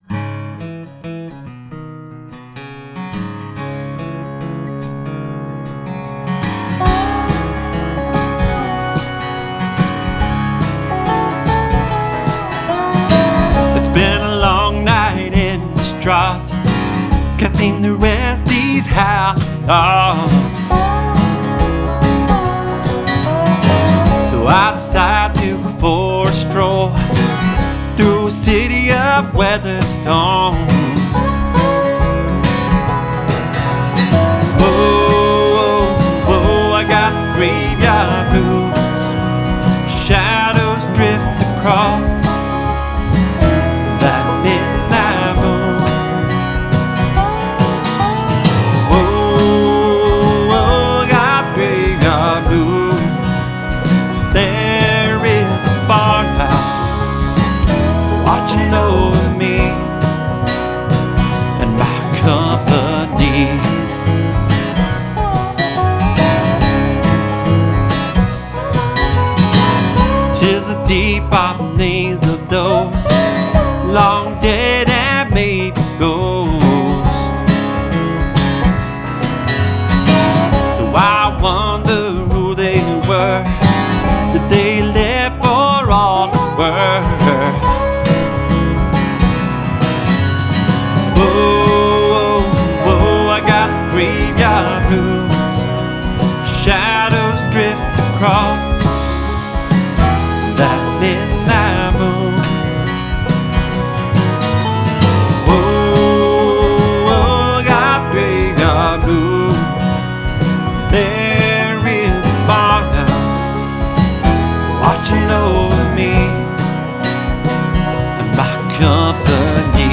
part reflective/campy halloween